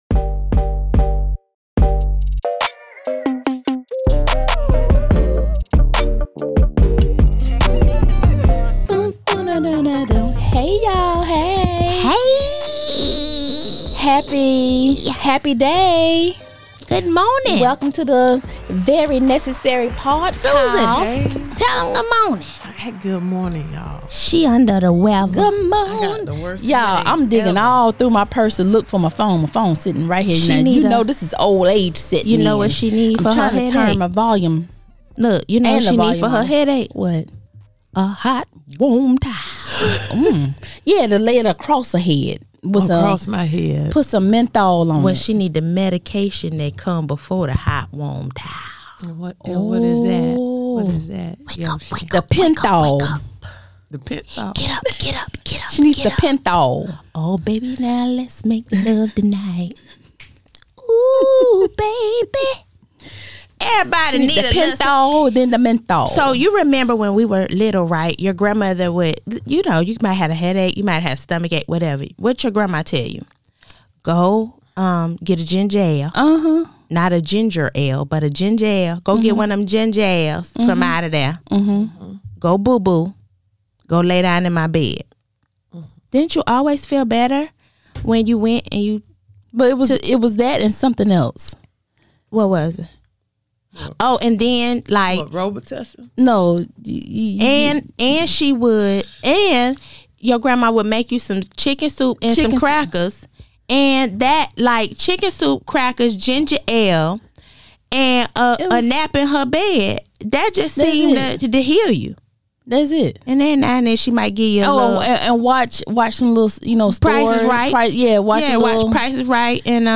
The ladies have a lot to say about the recent election results.